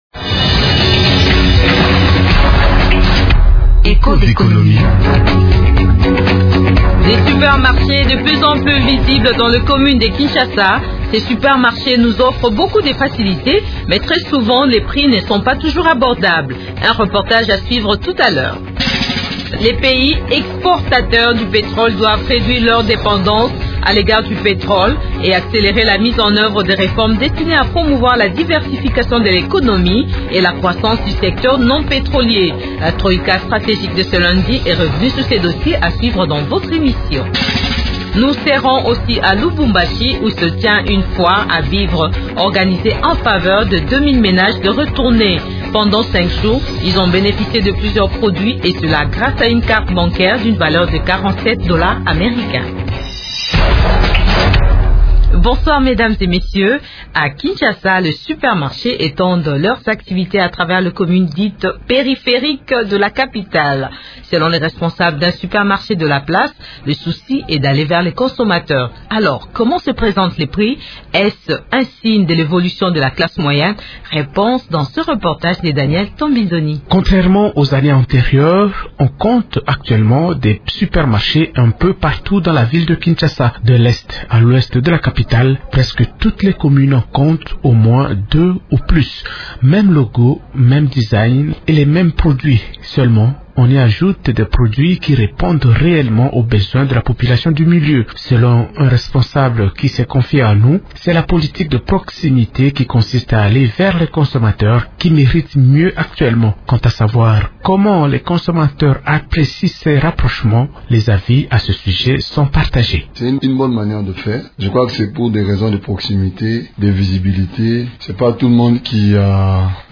Dans un autre sujet, ce magazine économique revient sur la réunion de la Troïka stratégique qui estime que les pays exportateurs du pétrole doivent réduire leur dépendance à l’égard du pétrole et accélérer la mise en œuvre des réformes destinées à promouvoir la diversification de l’économie et la croissance du secteur non pétrolier.